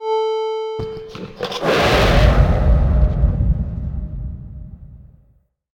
spearYellRumbleVoicePA.ogg